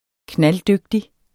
Udtale [ ˈknalˈdøgdi ]